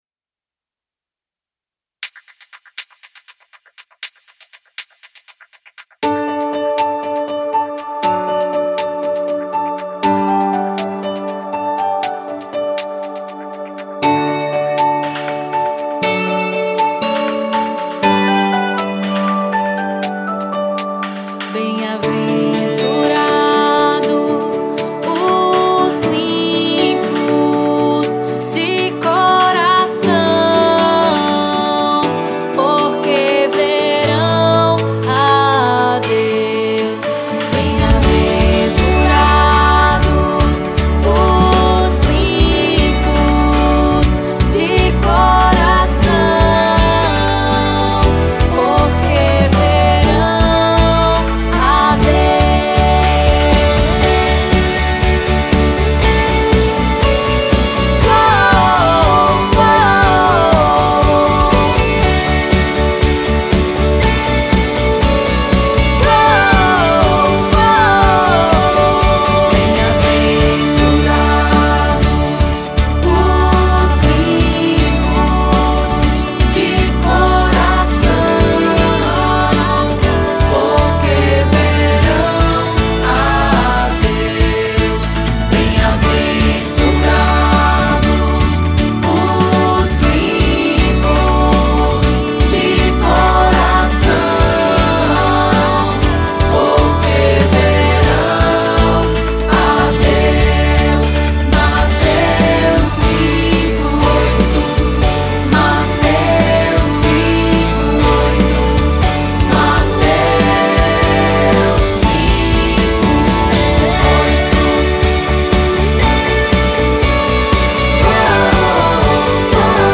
EstiloInfantil